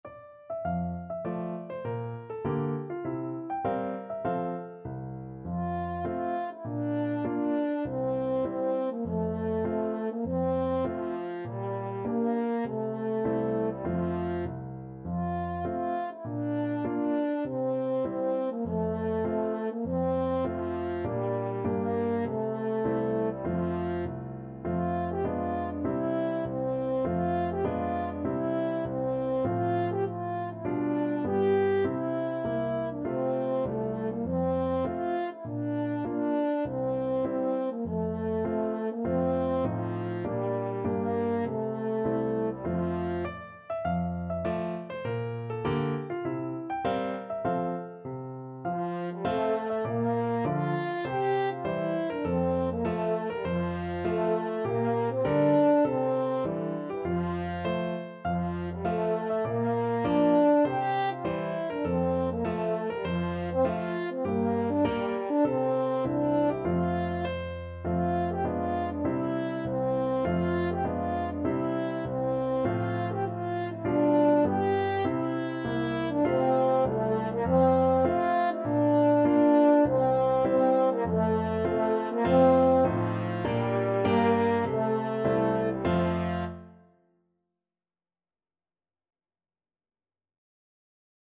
French Horn
F major (Sounding Pitch) C major (French Horn in F) (View more F major Music for French Horn )
4/4 (View more 4/4 Music)
D4-G5
Moderato
Traditional (View more Traditional French Horn Music)